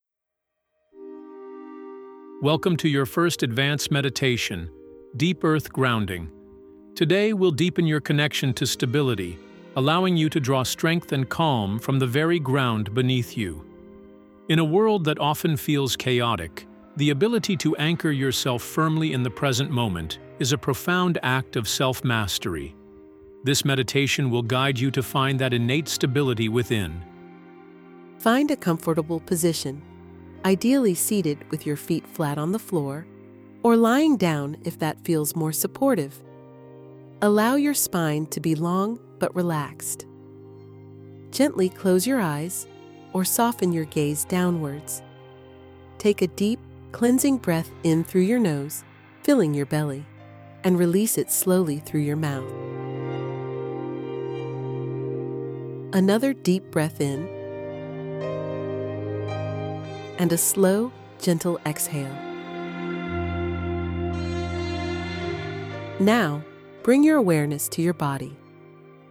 This guided meditation isn’t just a moment of calm—it’s a return to your center.
💛 Format: Audio meditation (MP3)